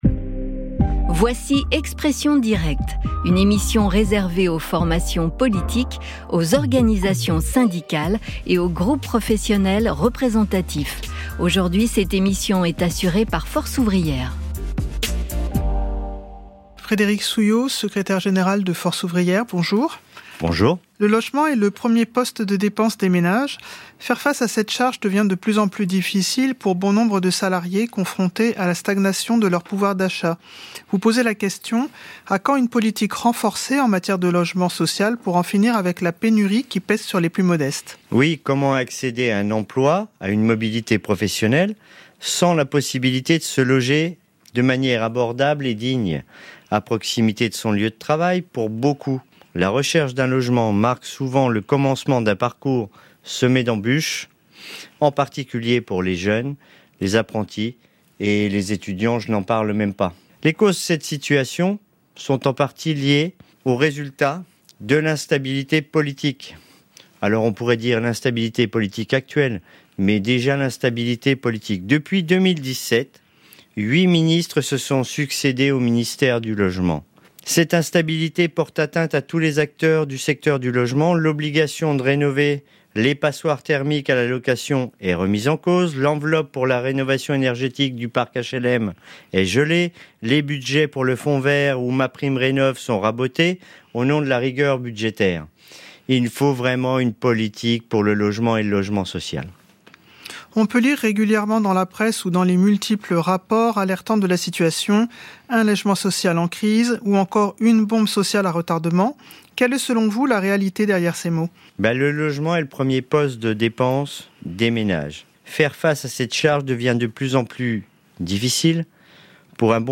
Interview de Frédéric Souillot, Secrétaire Général FO, expression directe du 13 décembre 2025.